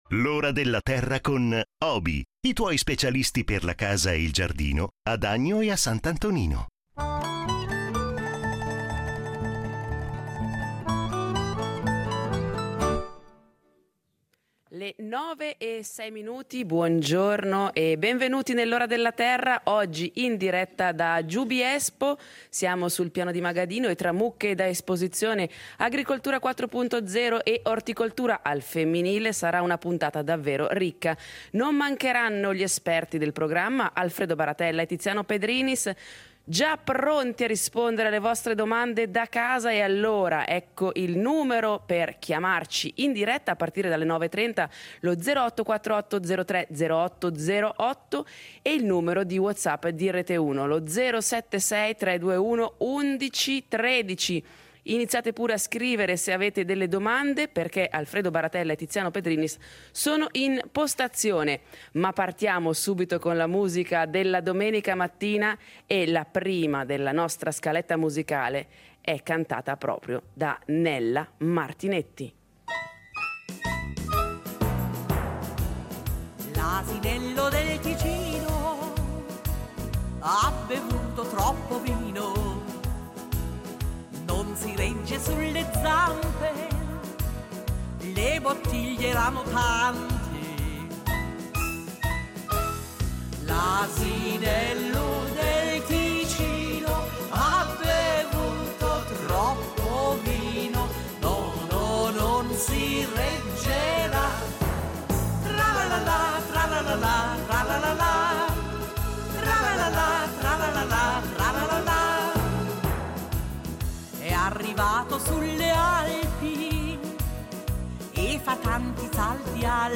Le conosceremo meglio in diretta dal Piano di Magadino.